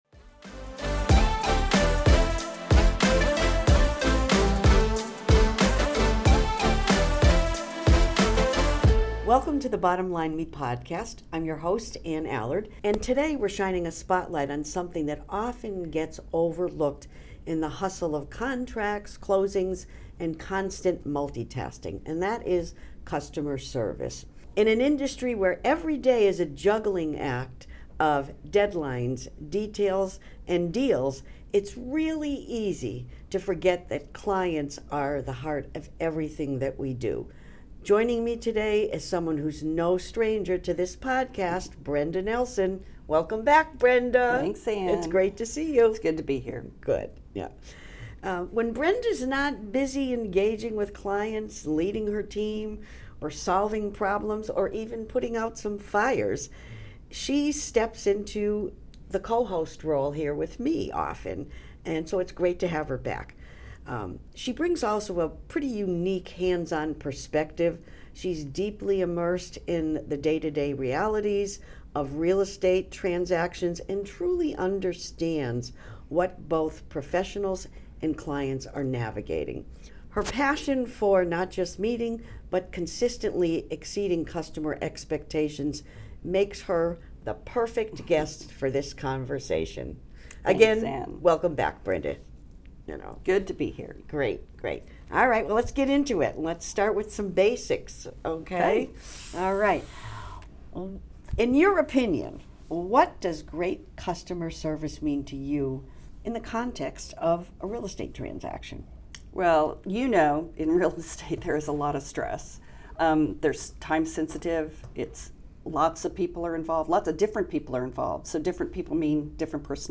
practical and insightful conversation on the art of customer service